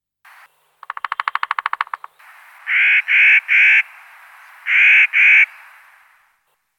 Pic tridactyle
Picoides tridactylus